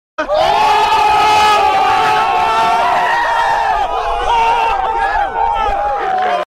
Download Funny Battle sound effect for free.
Funny Battle